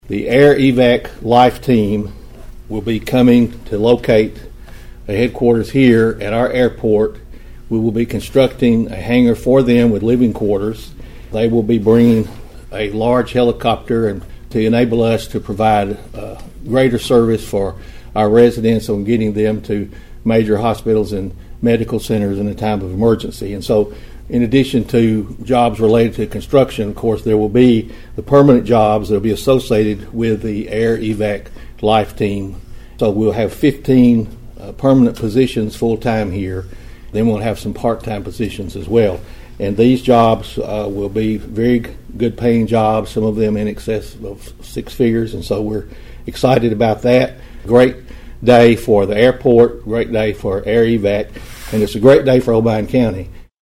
Obion County Mayor Steve Carr delivered the information during a press conference at the County Mayor’s Office in Union City.(AUDIO)